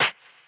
perc02.ogg